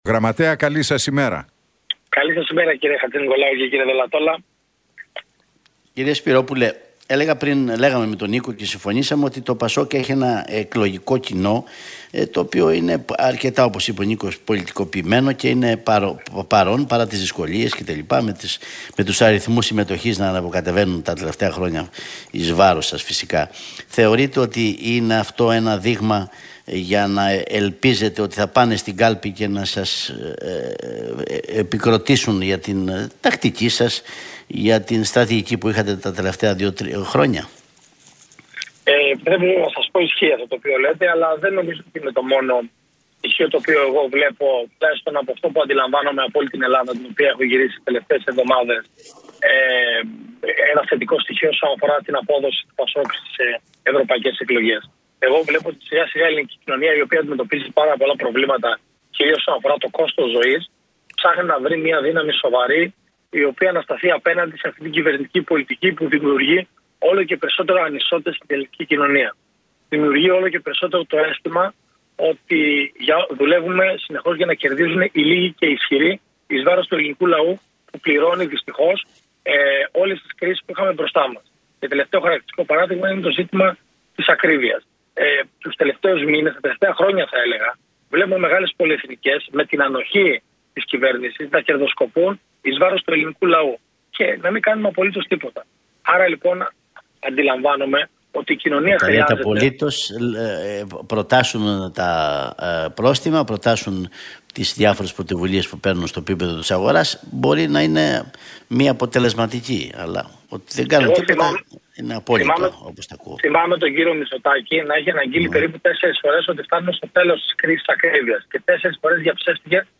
Σπυρόπουλος στον Realfm 97,8: Το ΠΑΣΟΚ δεν έχει καμία σχέση με την πολιτική του ΣΥΡΙΖΑ